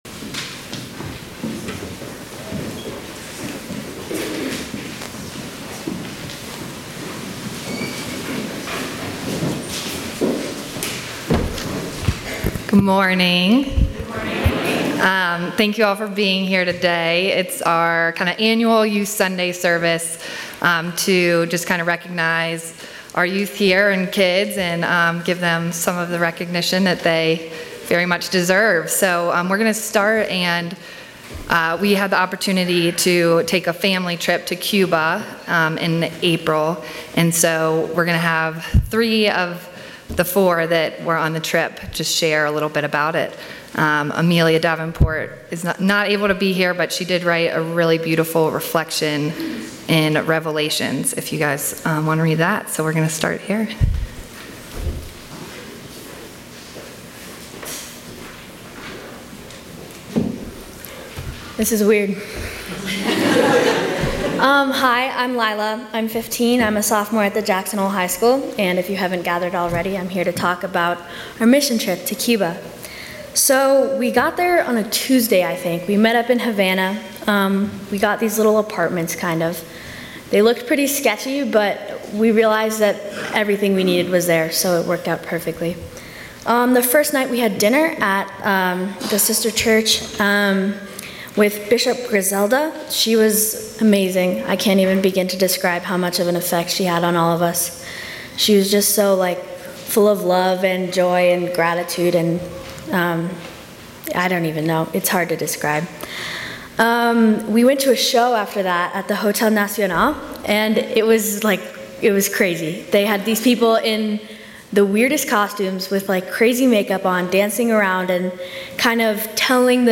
Hear from the Cuba Youth Missionaries